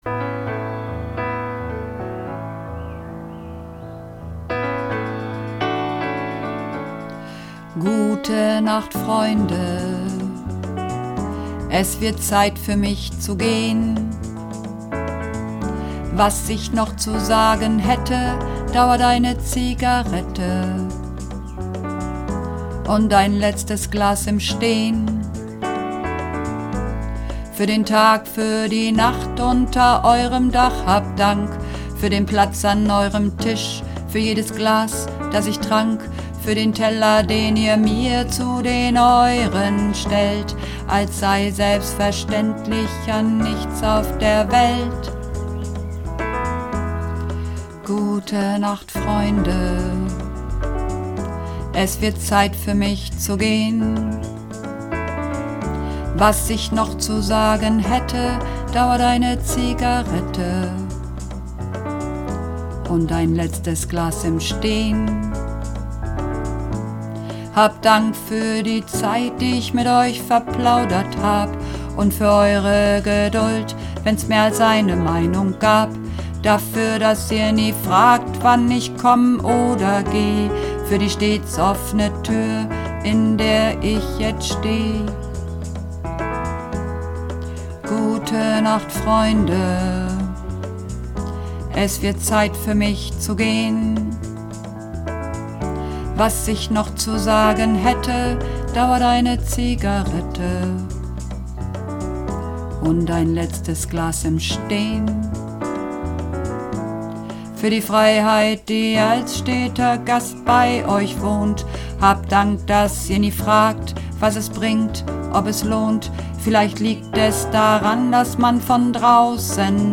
Männer